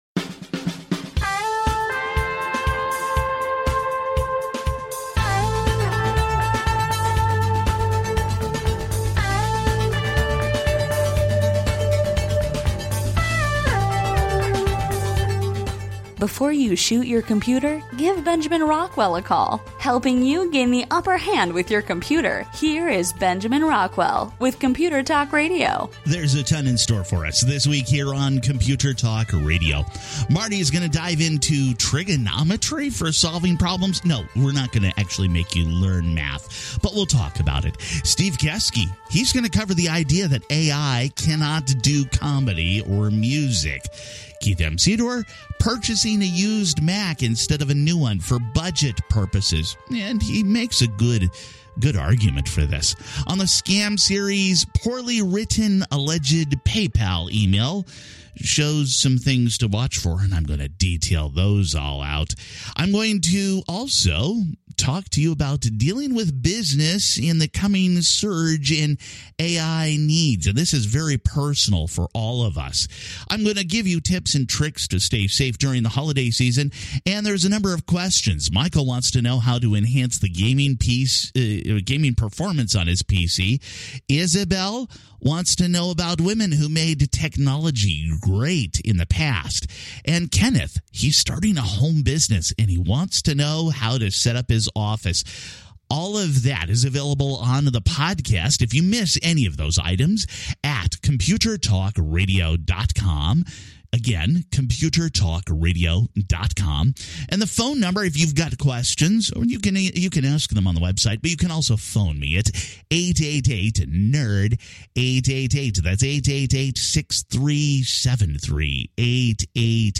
Computer Talk Radio is a nationally syndicated broadcast radio program on computers and technology, and how they impact your life.